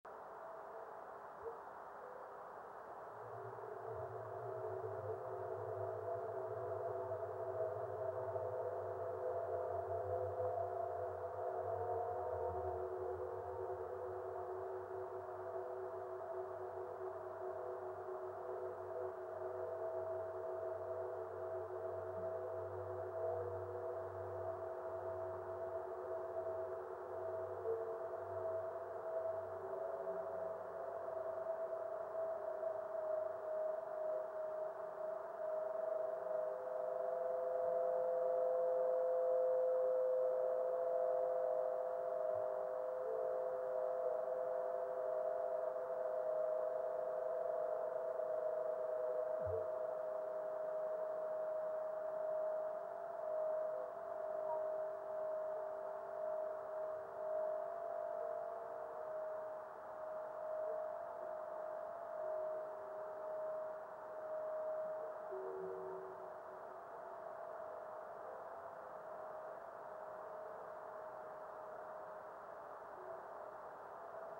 Observer's comments:  Lovely sound.
Full duration of sound.